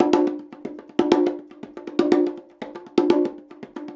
Index of /90_sSampleCDs/USB Soundscan vol.36 - Percussion Loops [AKAI] 1CD/Partition B/13-120BONGOS
120 BONGOS5.wav